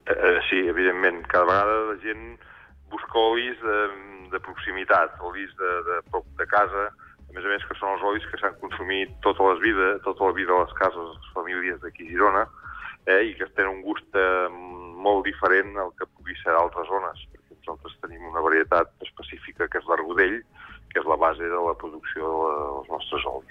EntrevistesSupermatí